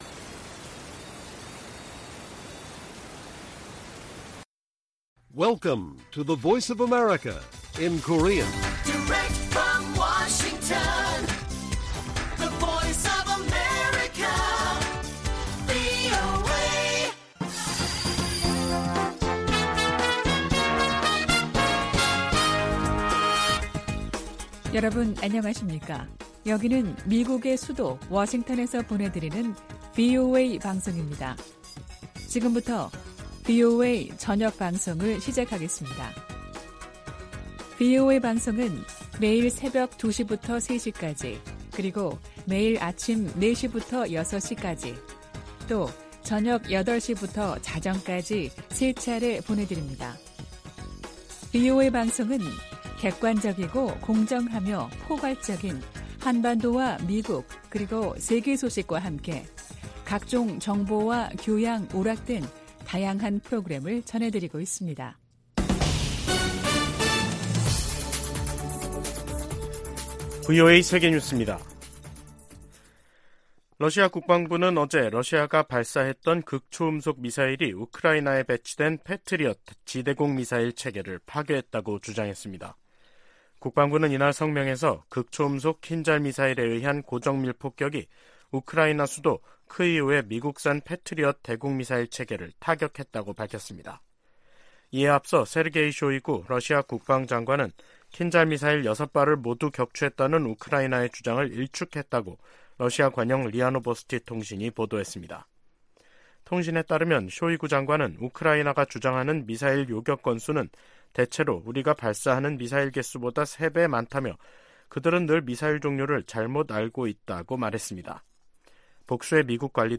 VOA 한국어 간판 뉴스 프로그램 '뉴스 투데이', 2023년 5월 17일 1부 방송입니다. 로이드 오스틴 미 국방장관은 상원 청문회에서 한국에 대한 확장억제 강화조치를 취하는 중이라고 밝혔습니다. 북한은 우주발사체에 위성 탑재 준비를 마무리했고 김정은 위원장이 '차후 행동계획'을 승인했다고 관영매체들이 전했습니다. 미 국무부가 화학무기금지협약(CWC) 평가회의를 맞아 북한이 생화학무기 프로그램을 보유하고 있다는 평가를 재확인했습니다.